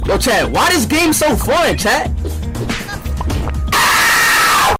ishowspeed why this game so fun chat Meme Sound Effect